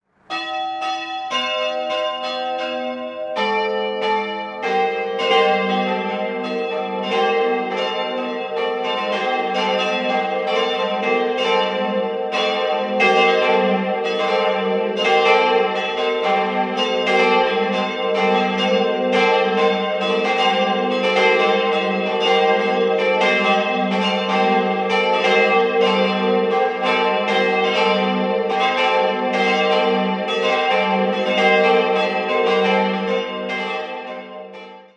4-stimmiges Geläute: g'-a'-c''-e''
bell
Nach den mir vorliegenden Daten hat die kleine Glocke den Ton e''+1. Der reale Klangeindruck kann dies jedoch nicht bestätigen, denn sie steht deutlich tiefer und bildet mit der nächstgrößeren c'' eher eine kleine anstatt einer großen Terz.